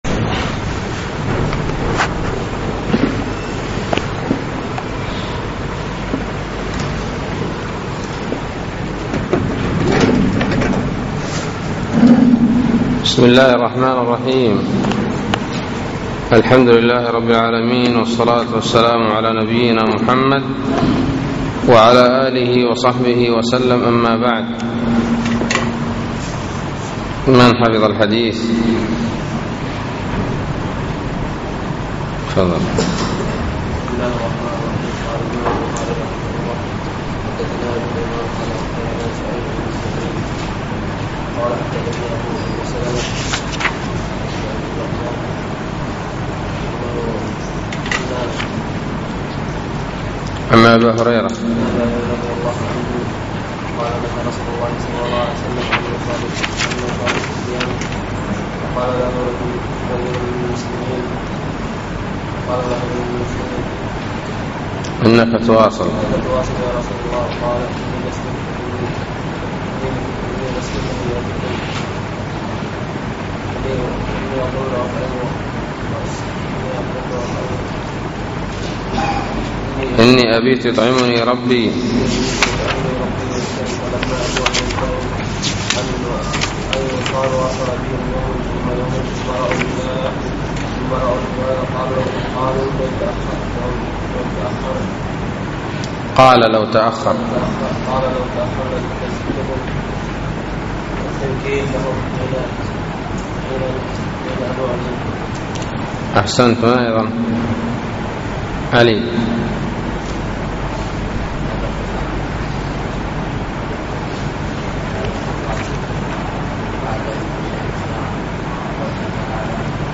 الدرس الرابع والثلاثون : باب من أقسم على أخيه ليفطر في التطوع ولم يرد عليه القضاء، إذا كان أوفق له